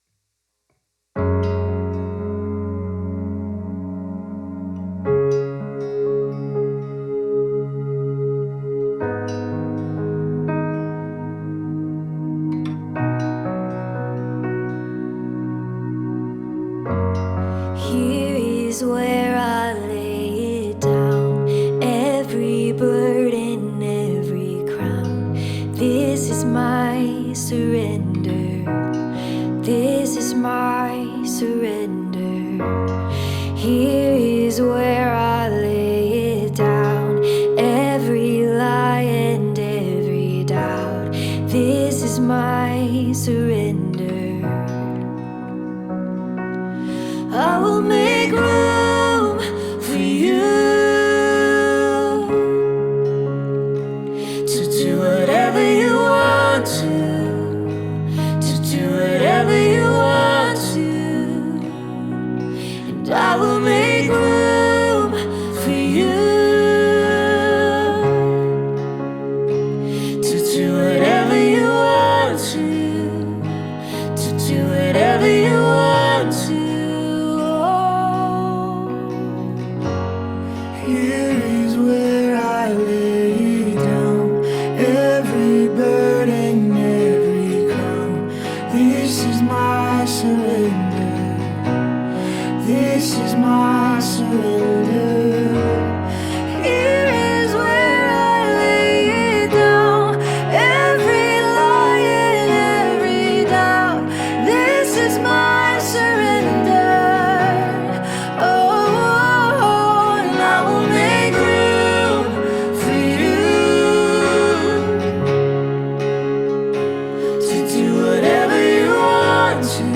1. Sunday Worship – First Song: